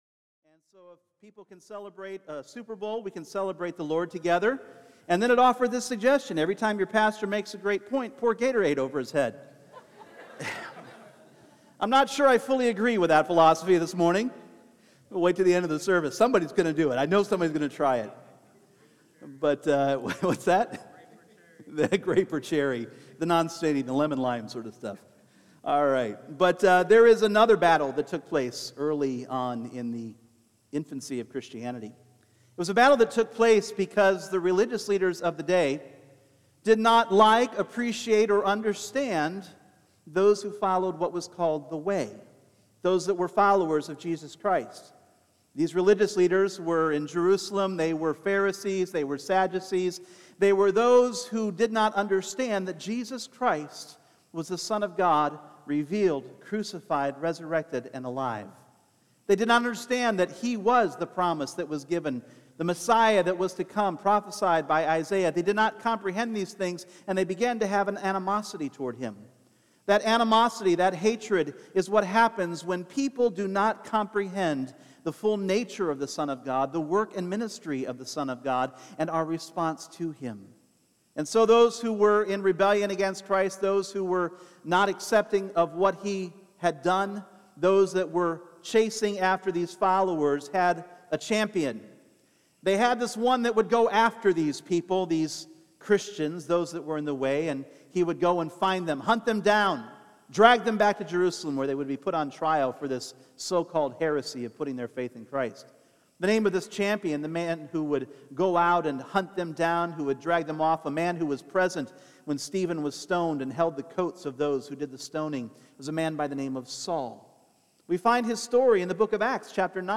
Individual Messages Service Type: Sunday Morning Is "sincerity" the best measure of our pursuit of faith?